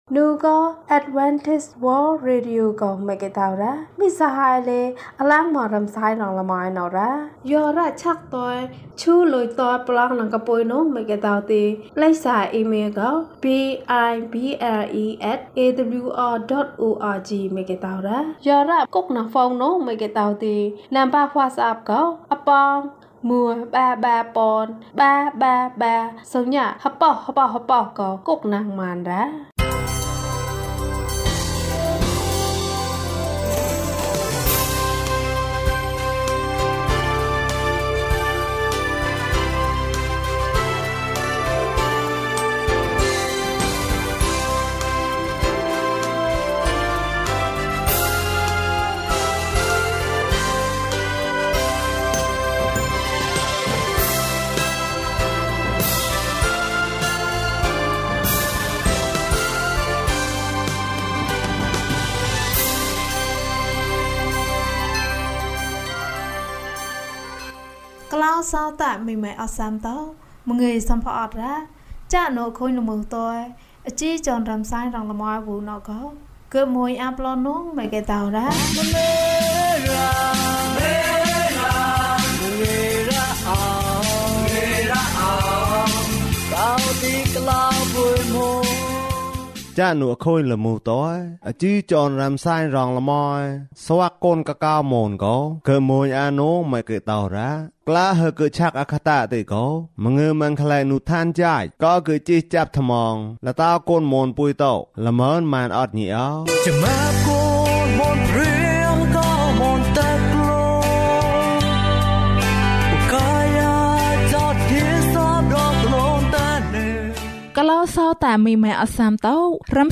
ခရစ်တော်ထံသို့ ခြေလှမ်း ၂၅။ ကျန်းမာခြင်းအကြောင်းအရာ။ ဓမ္မသီချင်း။ တရားဒေသနာ။